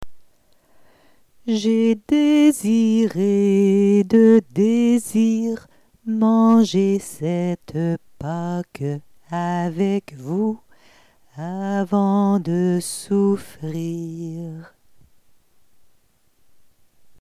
Espace d'écoute des uns et des autres, qu'on pourra conclure en chantant d'une manière méditative le verset de Luc, désormais porteur des expériences entendues.